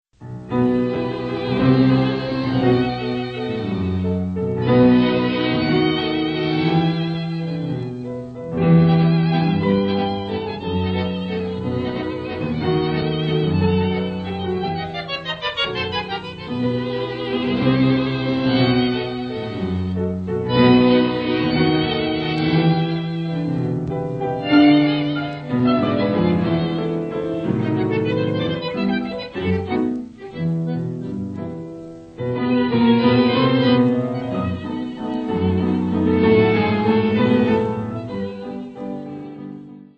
Vals